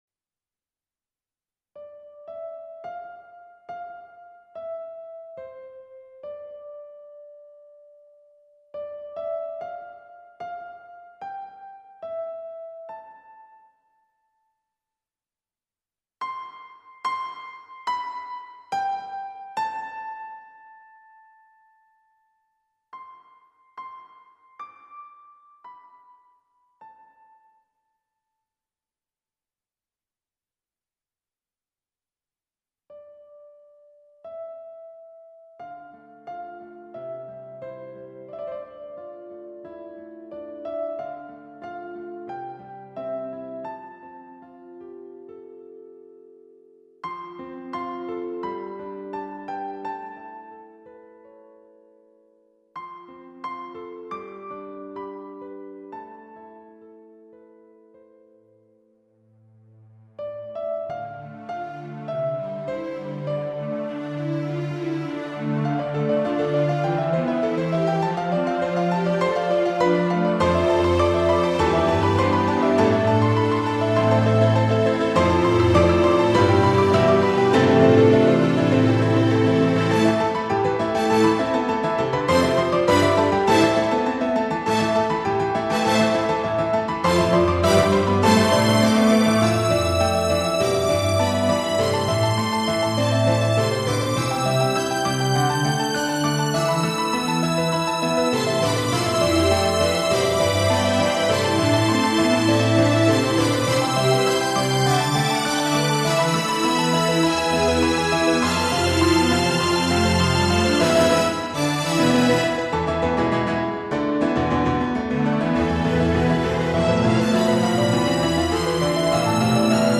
YAMAHA MU90にて録音(3.15 MB)
ピアノとストリングスがくんずほぐれつにからみあって、わやくちゃな中にも 妙なるハーモニーが・・・・。